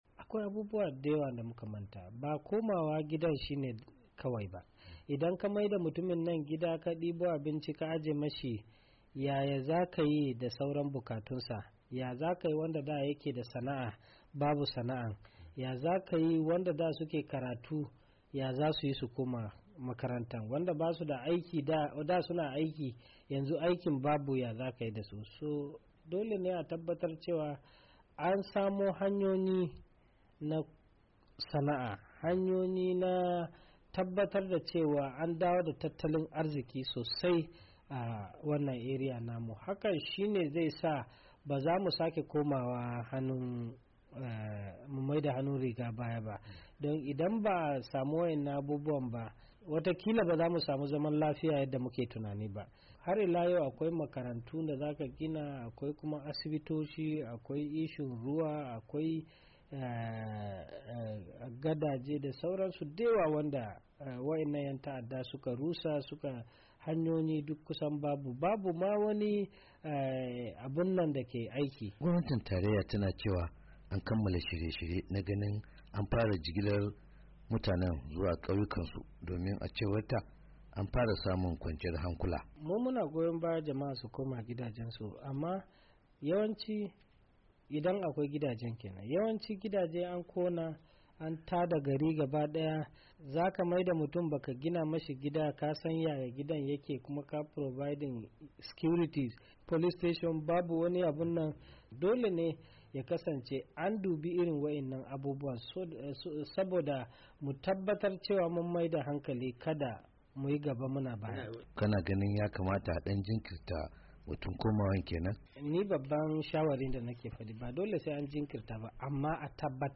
A wata hira da manema labarai a garin Gombe, dan majalisar wakilan yace samar da ofisoshin jami'an tsaro. makarantu, cibiyoyin ayyukan lafiya dakuma gina dakunan kwanan mutane nada cikin abubuwan da ya zama tilas a samar dasu kafin a fara mayarda mutane garuruwansu.
Saurari hirar mataimakin kwamitin samar da agajin gaggawa da manema labarai.